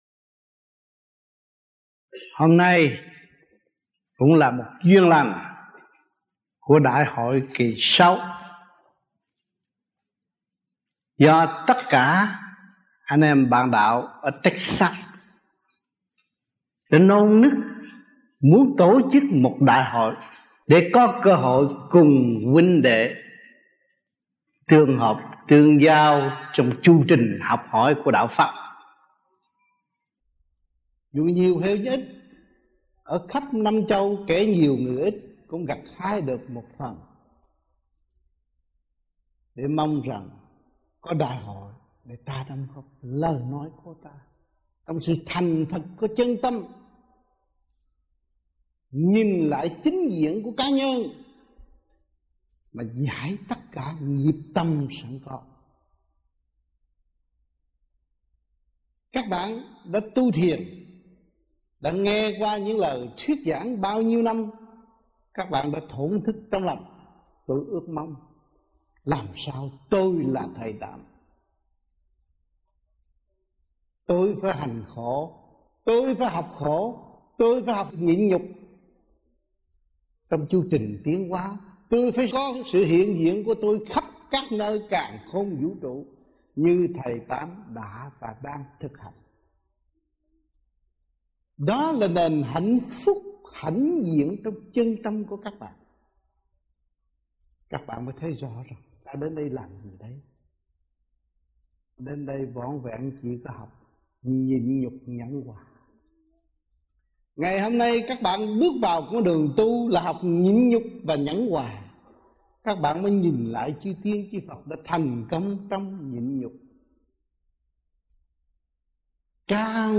Băng Giảng Và Vấn Đạo Tại Những Đại Hội Vô Vi Quốc Tế